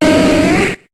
Cri de Noctali dans Pokémon HOME.